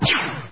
SFX激光枪音效下载
SFX音效